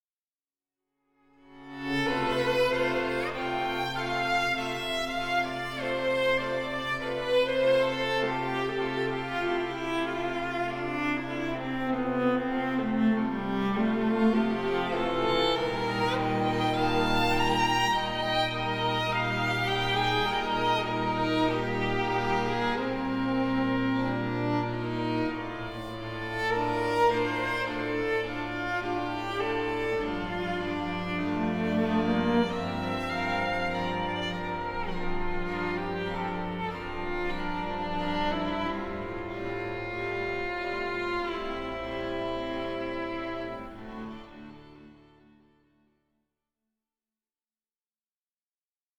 Adagio (1.29 EUR)